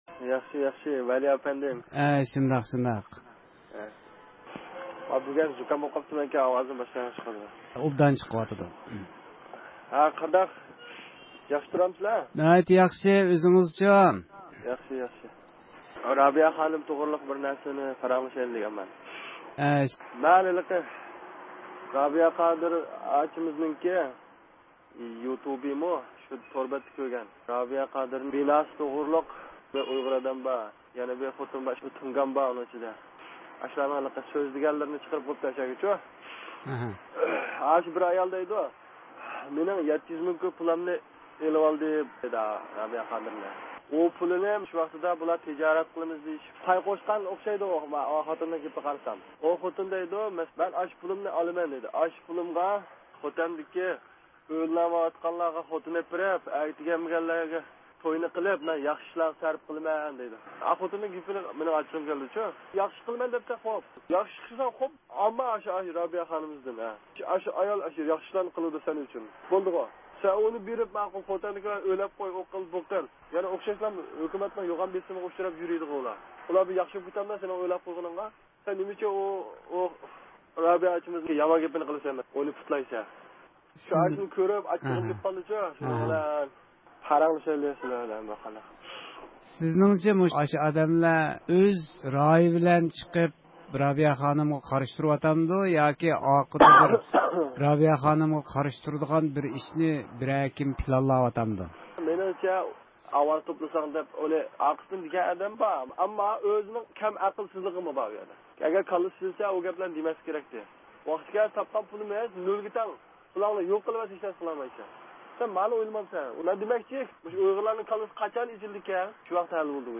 رادىئومىزنىڭ ھەقسىز تېلېفون لېنىيىسى ئارقىلىق تېلېفون قىلغان مەلۇم بىر ئۇيغۇر، رابىيە خانىم توغرىسىدا ۋە خىتاي كوممۇنىست پارتىيىسىنىڭ 17 - قۇرۇلتېيى ھەققىدە ئۆزىنىڭ كۆز قاراشلىرىنى ئوتتۇرىغا قويدى.